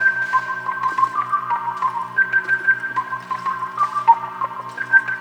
Back Alley Cat (Organ 02).wav